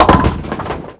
1 channel
bowling06.mp3